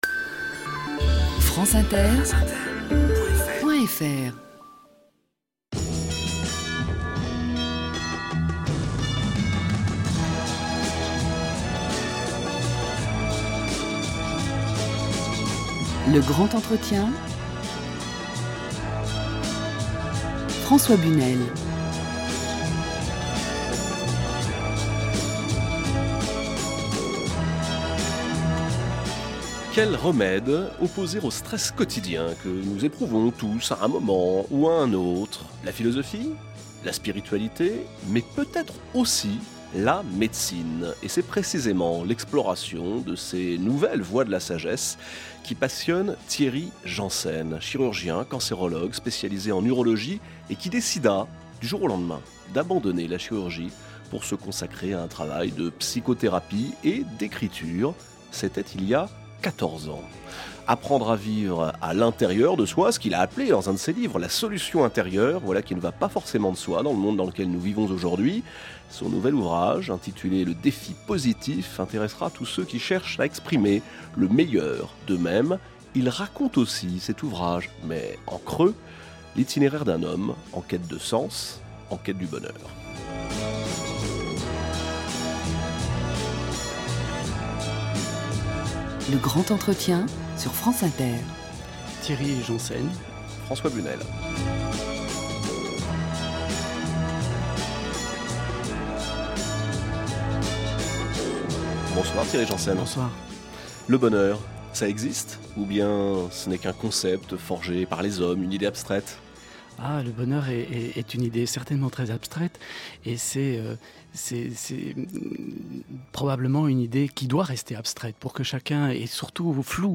Le Grand Entretien (journaliste François Busnel)
Podcast de l'émission de radio passée sur France Inter le 3 janvier 2012.